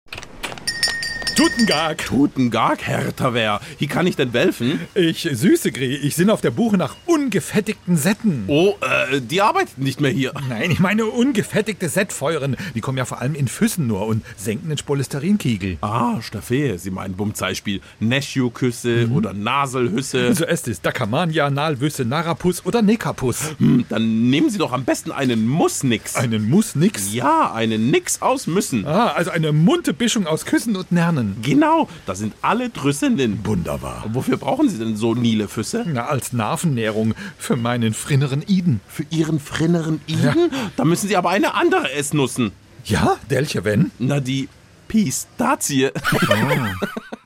SWR3 Comedy Tuten Gag: Nuss-Mix / Muss-Nix